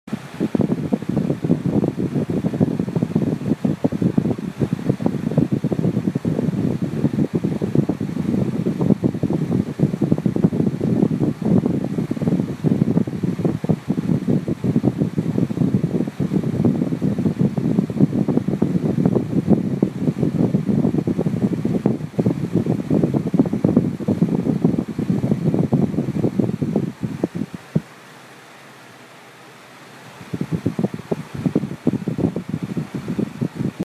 Field Recording #5
The sounds heard in this clip consists of my fan, music playing faintly from my neighbor’s house, distant clanging/beeping, and my housemates talking downstairs.
Recording-Fan.mp3